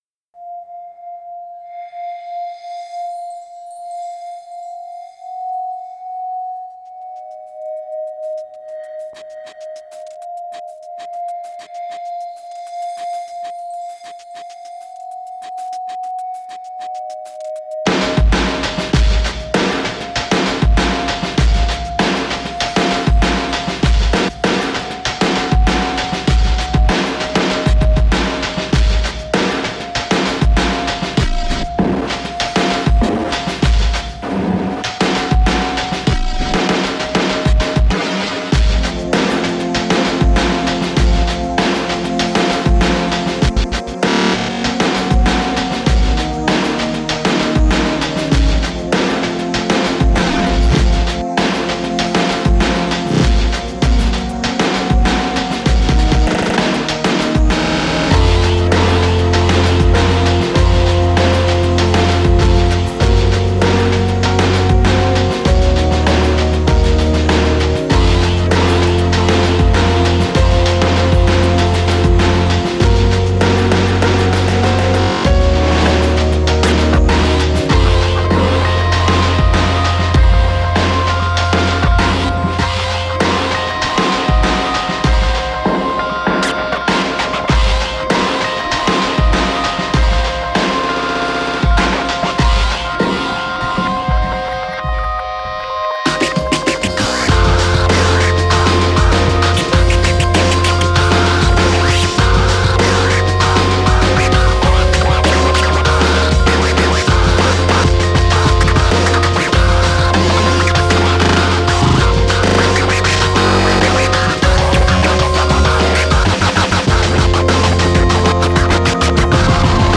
اینسترومنتال راک
موسیقی الکترونیک بیکلام